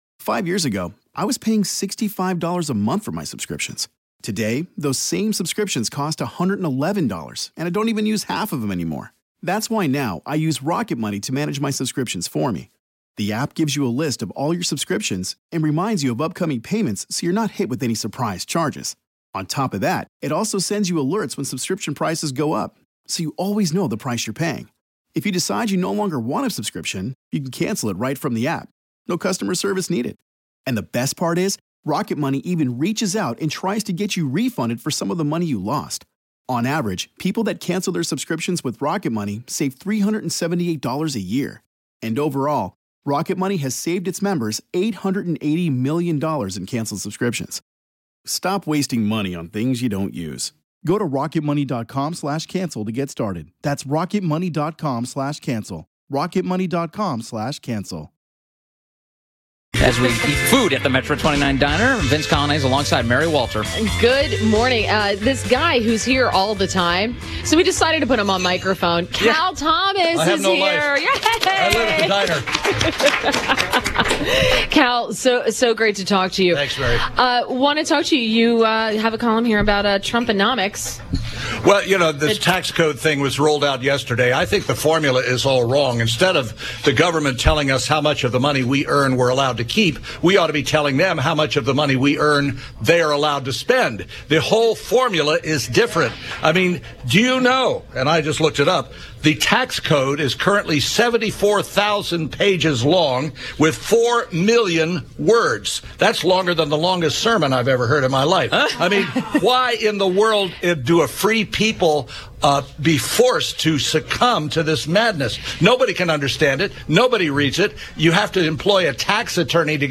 INTERVIEW – CAL THOMAS – Syndicated columnist — discuss tax reform